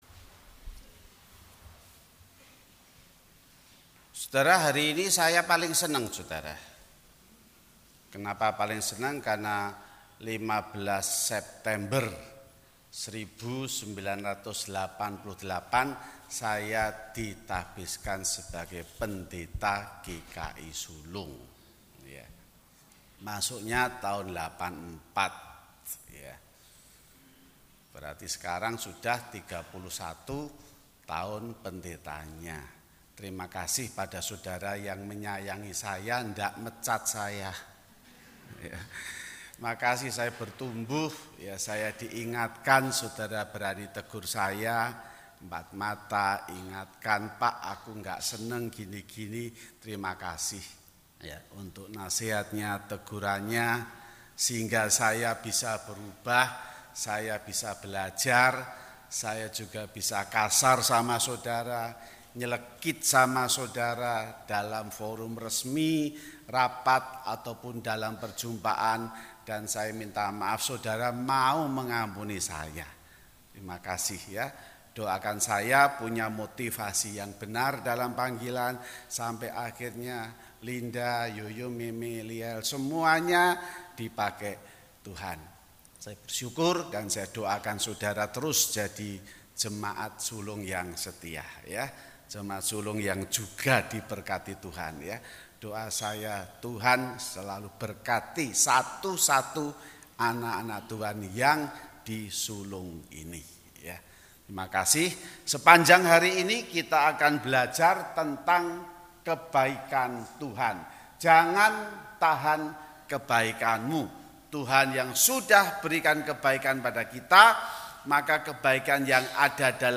Kebaktian Minggu: 15 September 2019